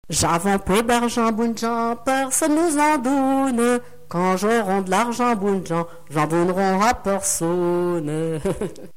Couplets à danser
Pièce musicale inédite